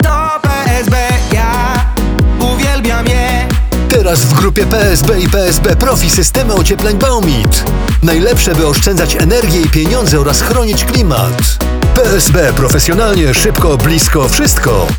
• 15-sekundowe spoty produktowe, emitowane na antenach stacji: RMF FM, RMF Maxxx, RMF Classic, Radio ZET, Antyradio, Meloradio, Chilli Zet, VOX FM oraz w rozgłośniach lokalnych.